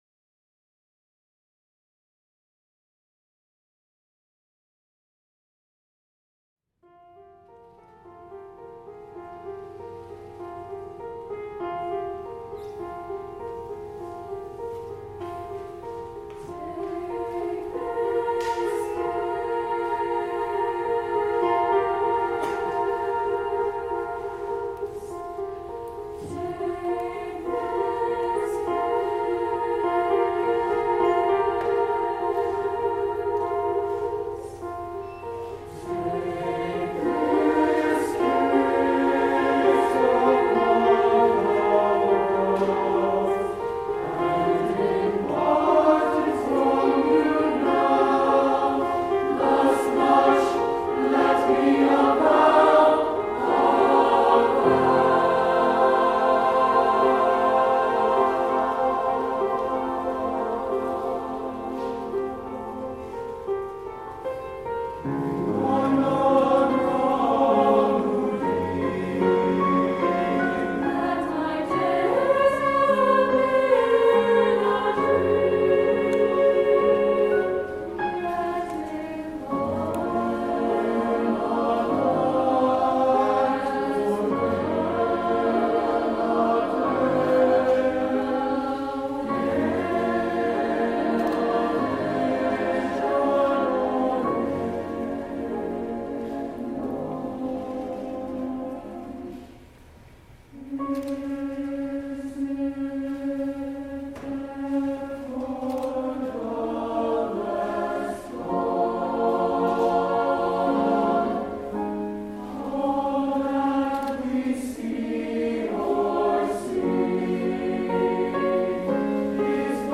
SATB Chorus & Piano